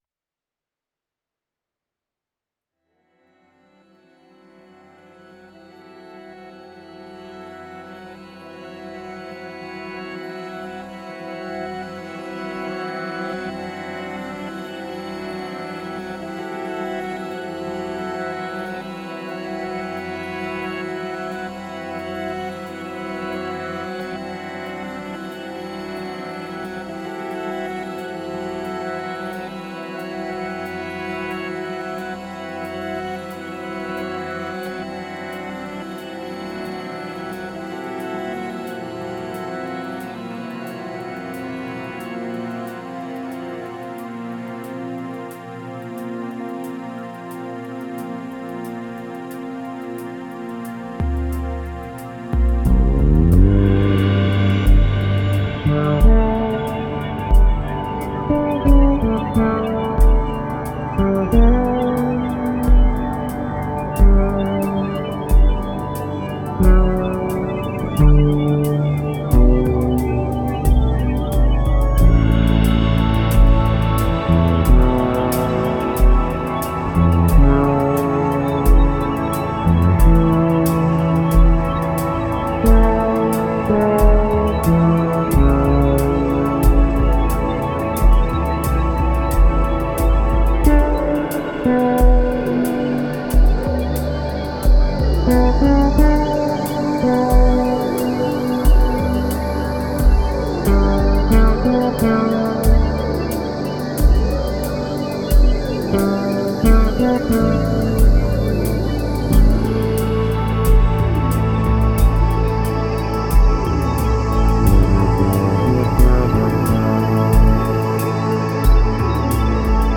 Seit Jahren meine beiden persönlichen Favoriten für die Aufnahme von Basslines bei der Studioarbeit. your_browser_is_not_able_to_play_this_audio API 512c und API 527A.